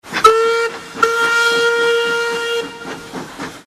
Steam